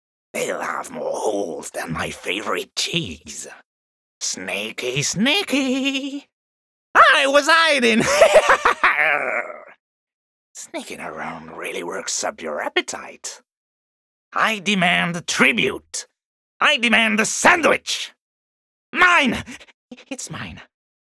15 - 60 ans - Baryton